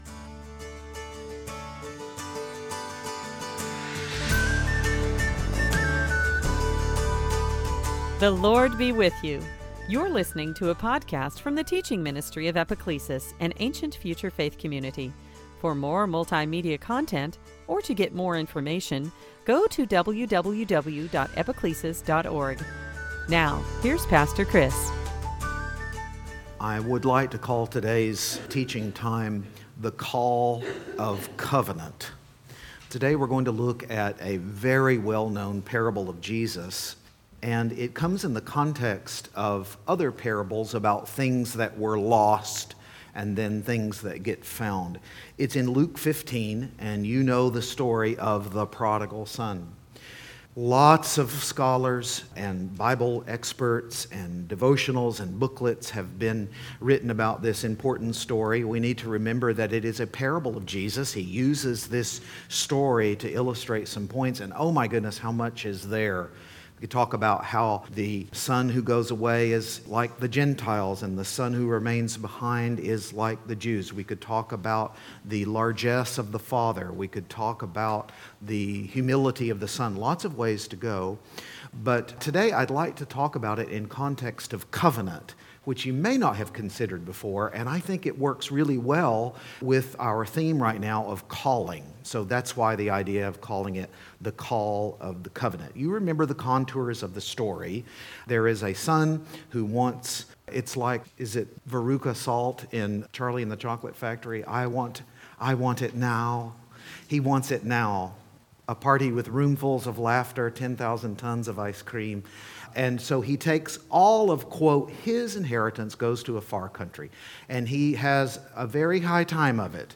Series: Sunday Teaching
Service Type: Lent